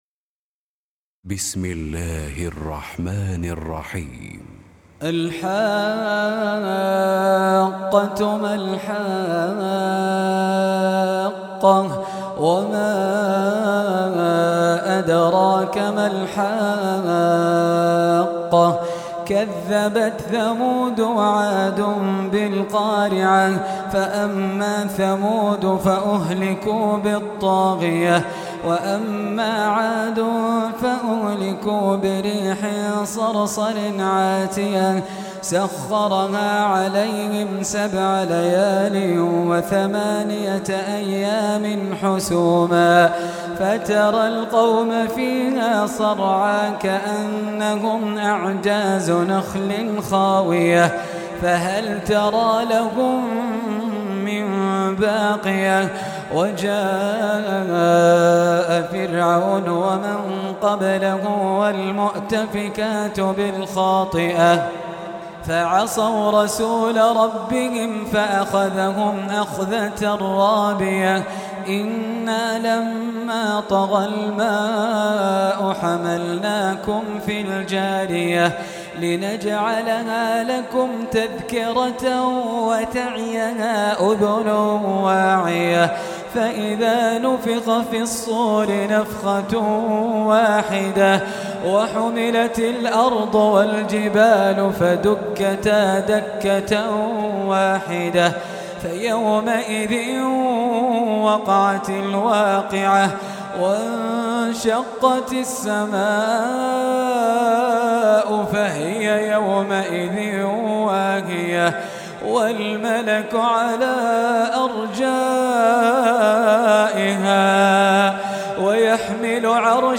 Surah Sequence تتابع السورة Download Surah حمّل السورة Reciting Murattalah Audio for 69. Surah Al-H�qqah سورة الحاقة N.B *Surah Includes Al-Basmalah Reciters Sequents تتابع التلاوات Reciters Repeats تكرار التلاوات